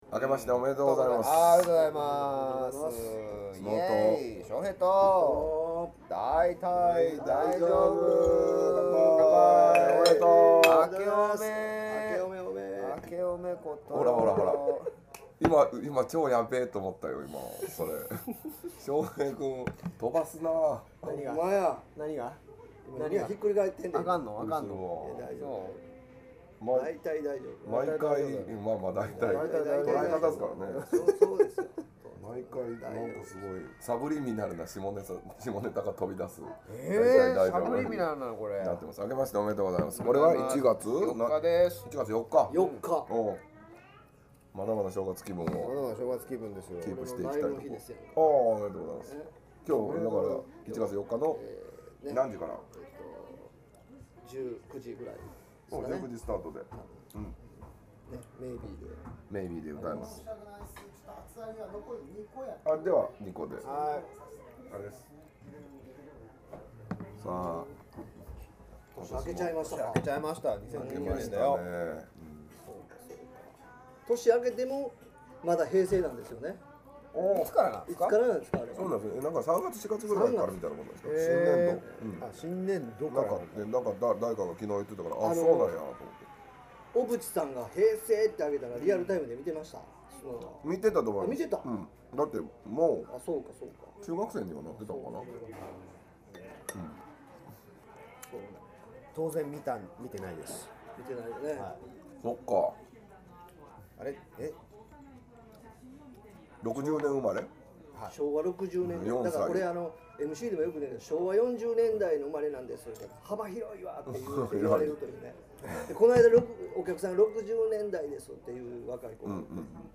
（１２月３日収録③）電池がきれてしまったので、2話続けてのエピソードに編集しました！